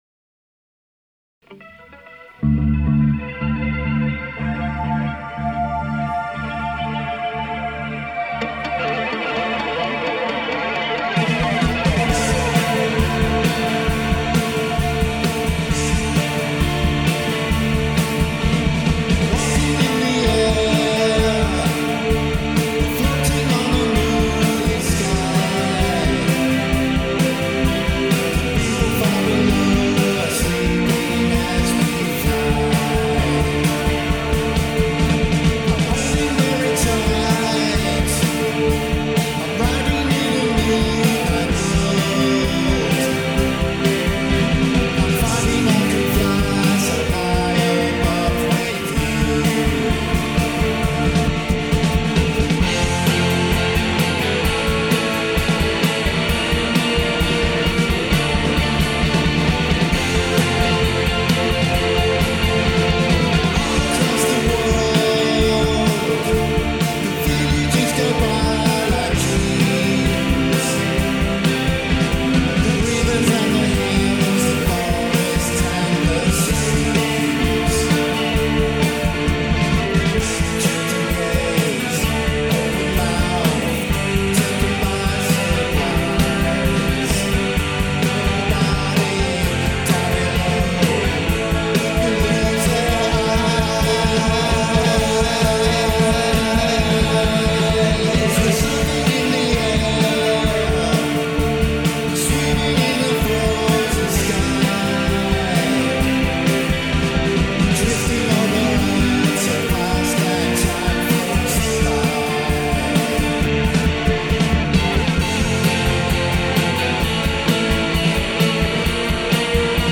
version of the xmas classic